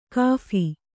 ♪ kāphi